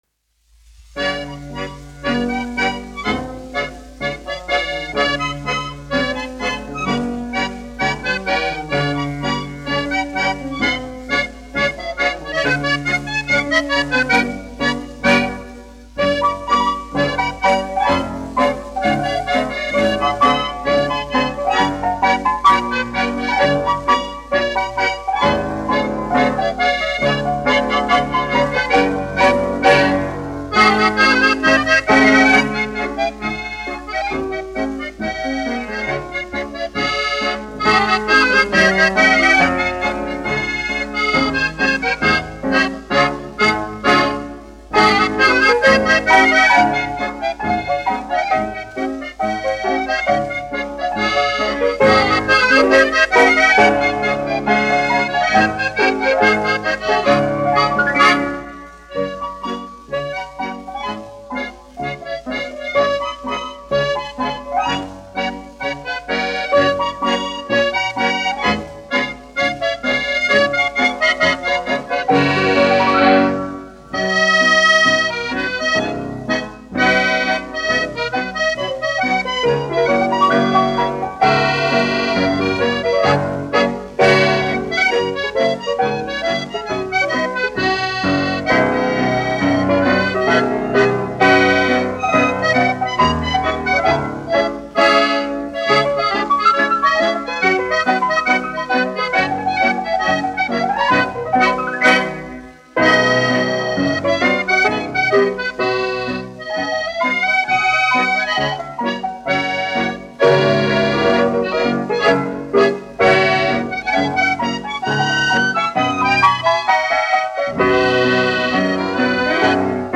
1 skpl. : analogs, 78 apgr/min, mono ; 25 cm
Polkas
Populārā instrumentālā mūzika
Akordeona un klavieru mūzika
Skaņuplate